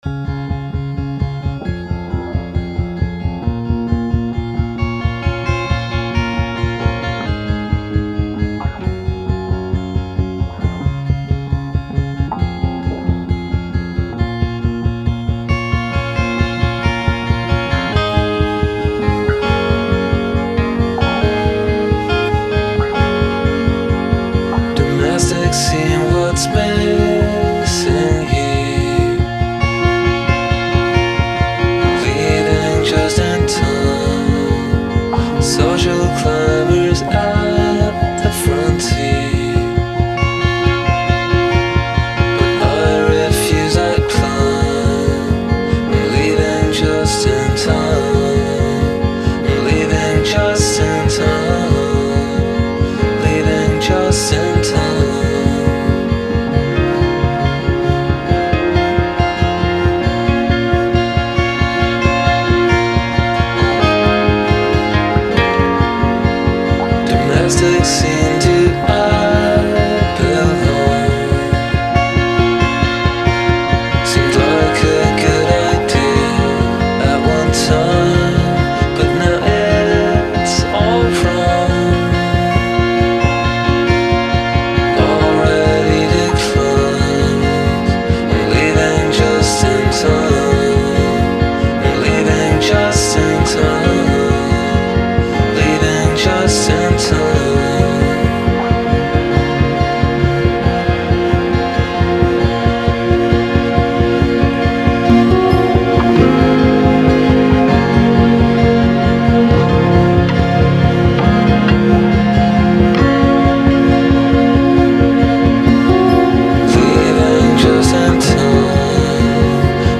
Keyboards
Guitar and vocals